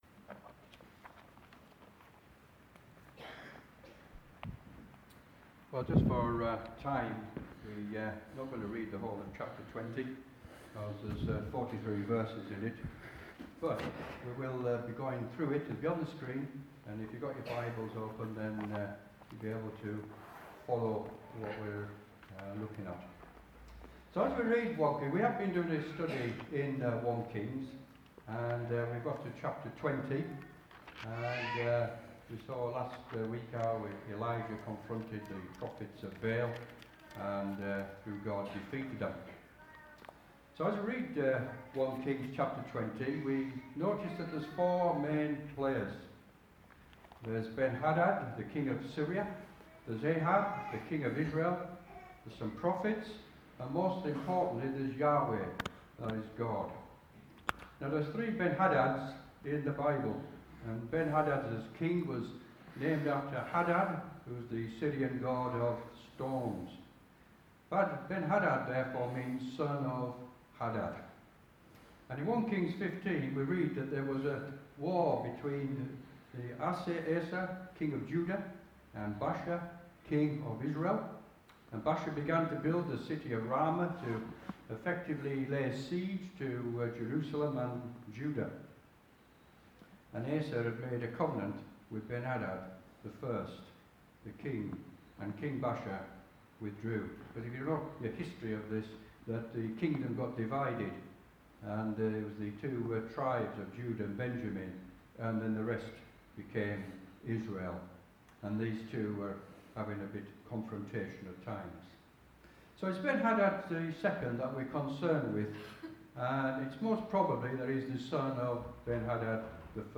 A message from the series "1 Kings."